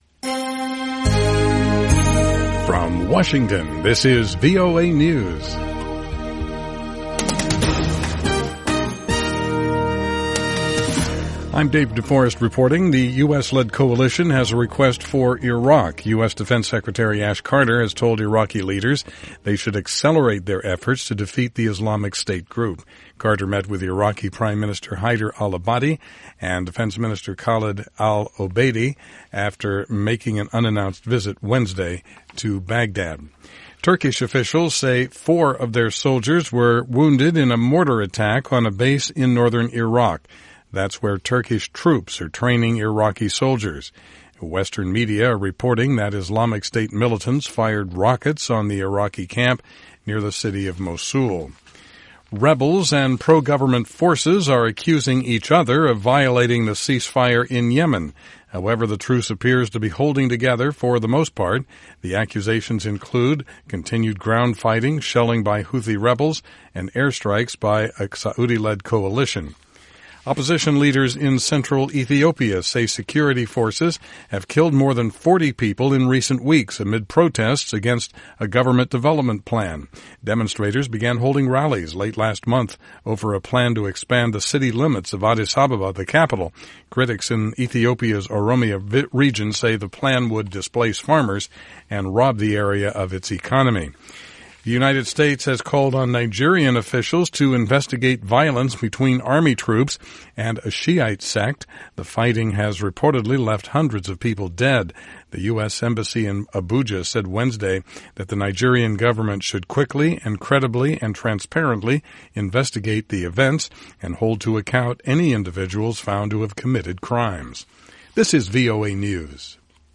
VOA English Newscast 2000 UTC December 16, 2015